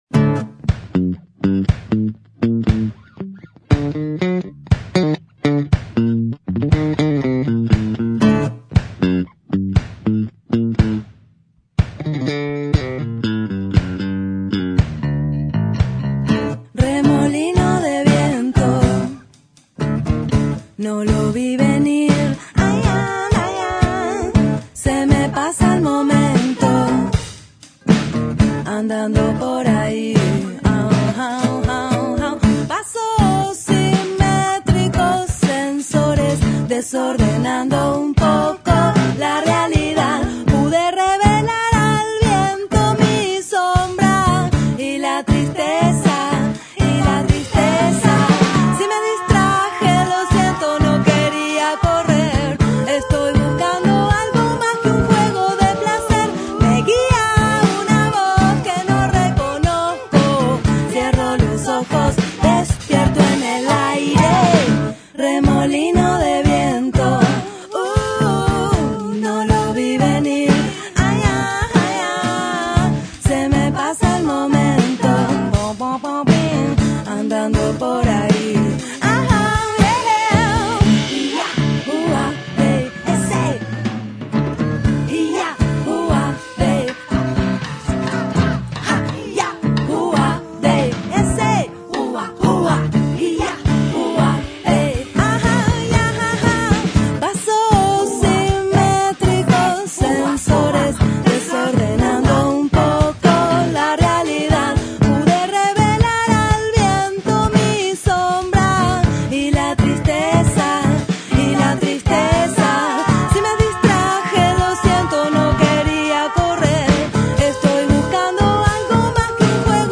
un disco muy personal de sonido orgánico. Canciones de alto vuelo melódico, ceremoniales y mántricas que reflejan un estado natural en cada momento del disco.